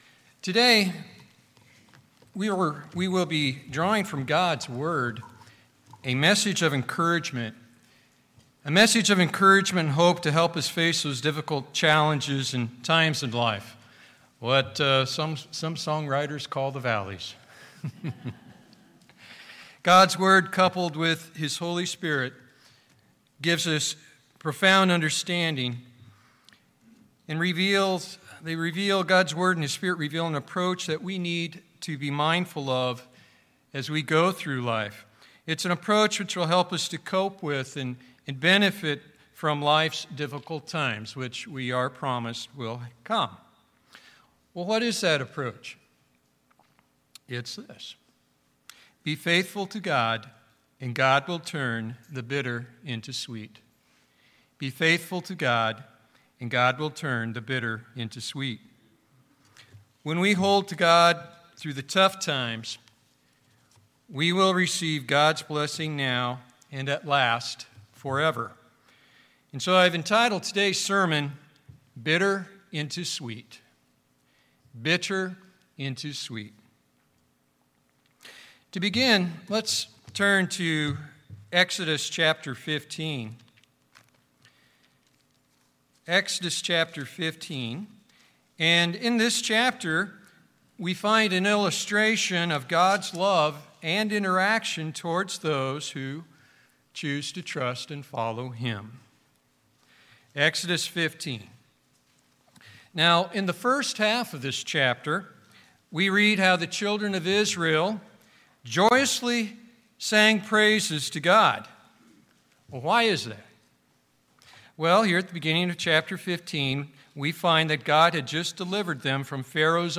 This sermon draws from God's Word a message of encouragement and hope to help us face those difficult challenges and times of life.